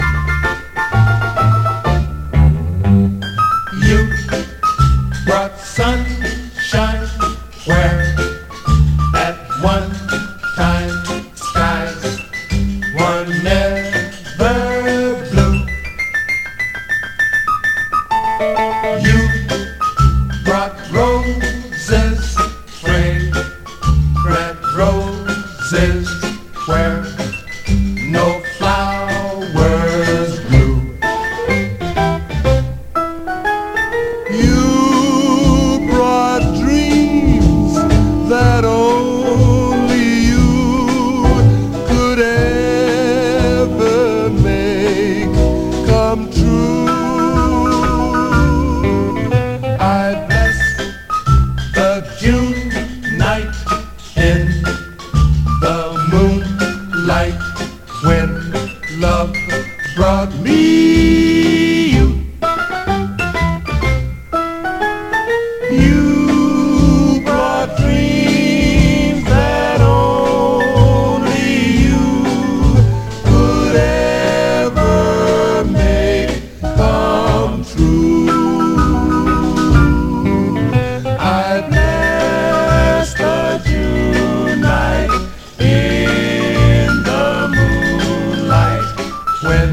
SOUL / RHYTHM & BLUES / JUMP BLUES
58年リリースのジャンプ・ブルース〜R&Bダンサー！
ブギー・ウギーの流れを汲んだアップテンポ・ジャンプ・チューンで、ピアノとホーンの掛け合いが最高に楽しい内容。